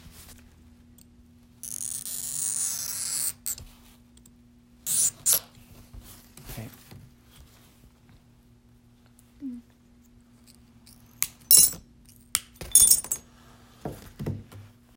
Glass Break 1